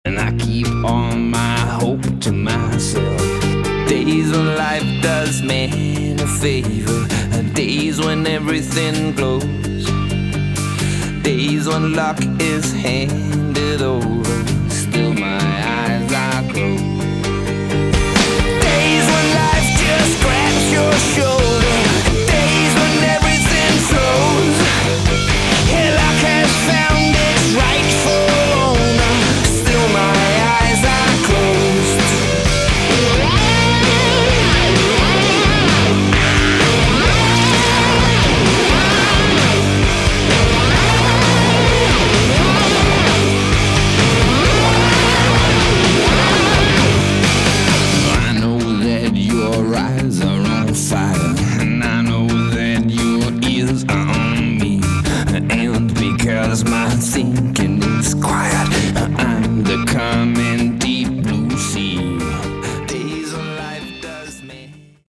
Category: Hard Rock
lead vocals, rhythm guitar
lead guitar, backing vocals
bass, backing vocals